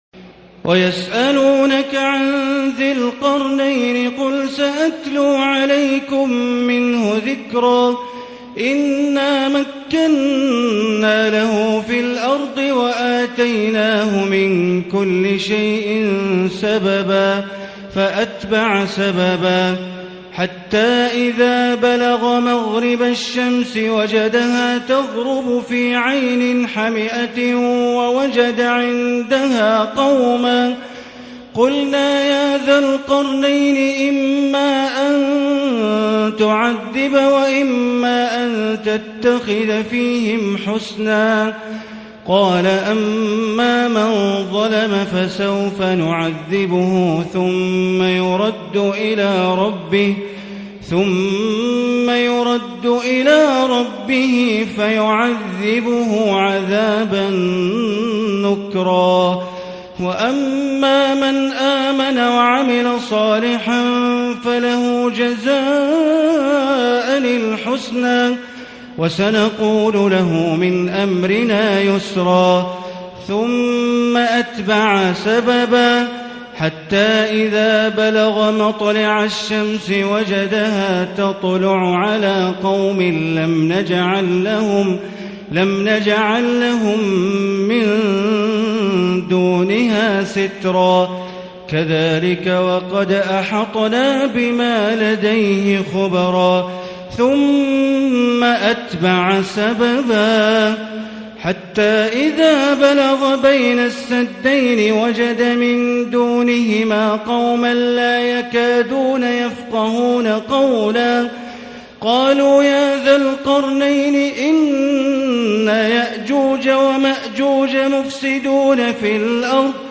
ليلة 15 رمضان 1440 من سورة الكهف و مريم من اية ٨٣ إلى نهاية سورة مريم > تراويح ١٤٤٠ هـ > التراويح - تلاوات بندر بليلة